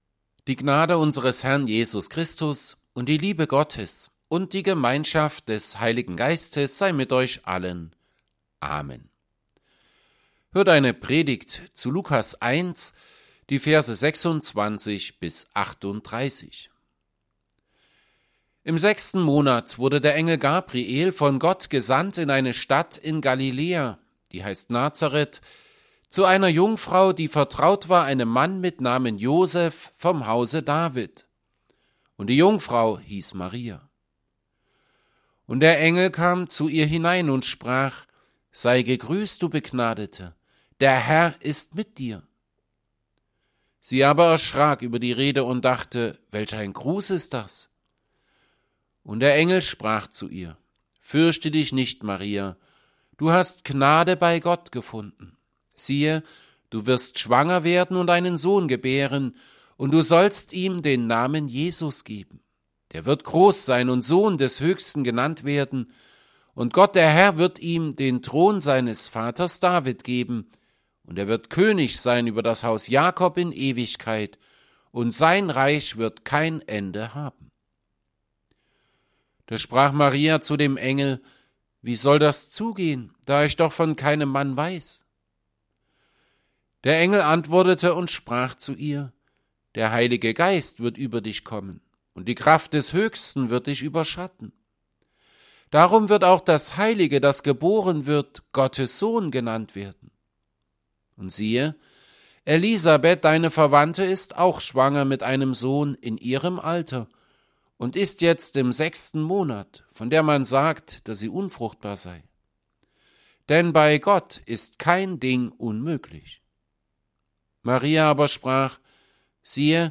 Predigt_zu_Lukas_1_26b38.mp3